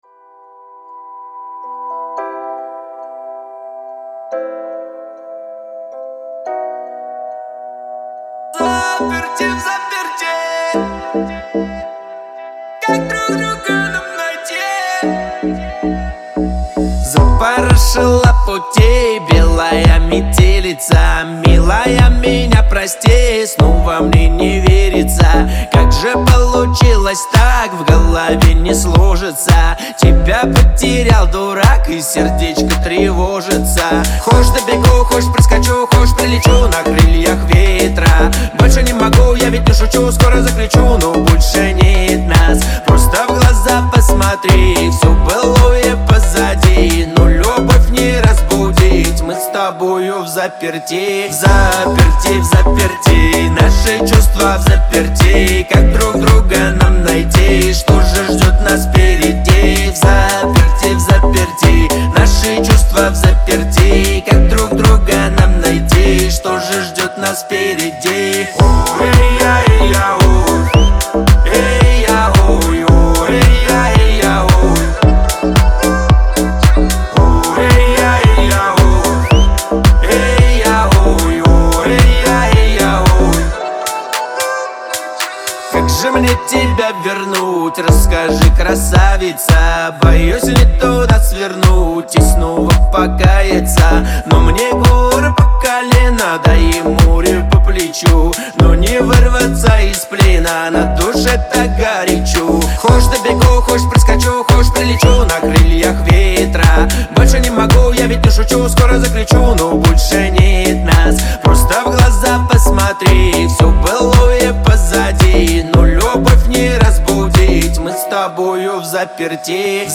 это мощный трек в жанре хип-хоп
отличается яркими битами и запоминающимися рифмами